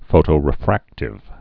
(fōtō-rĭ-frăktĭv)